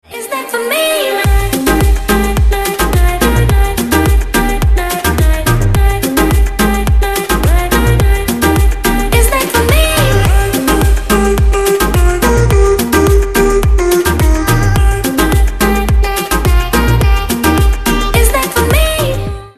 • Качество: 128, Stereo
поп
женский вокал
dance
Electronic
красивый женский голос